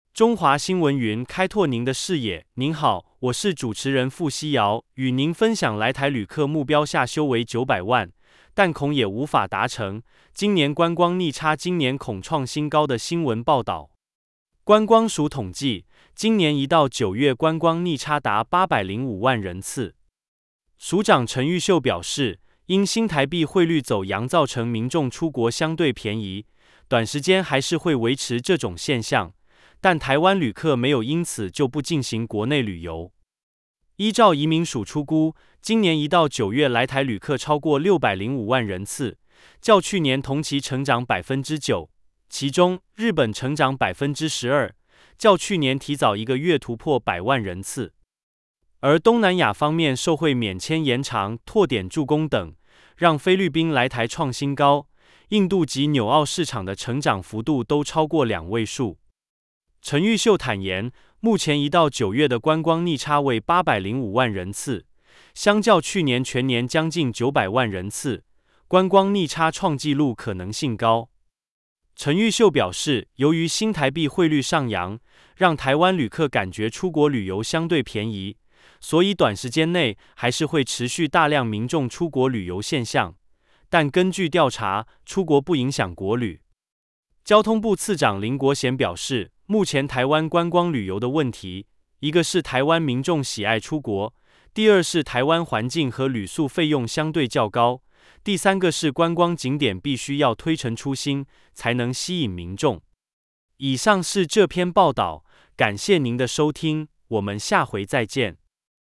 語音新聞